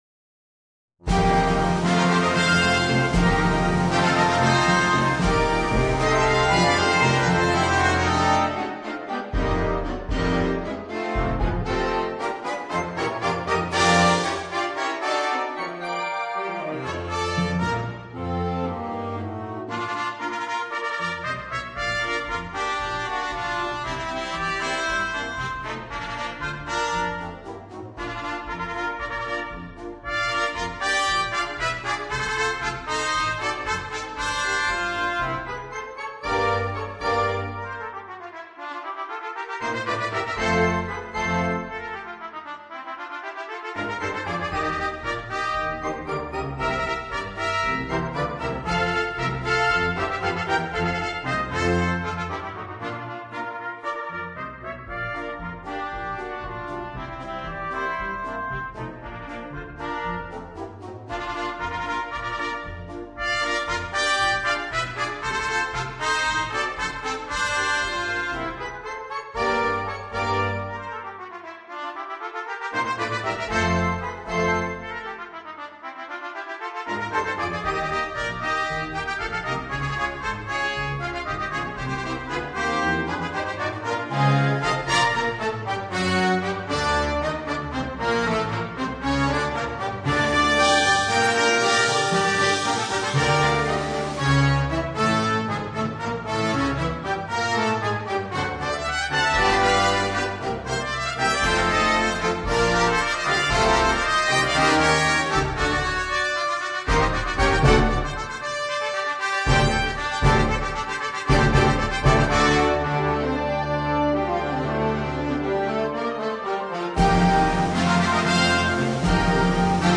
PER 2 TROMBE E BANDA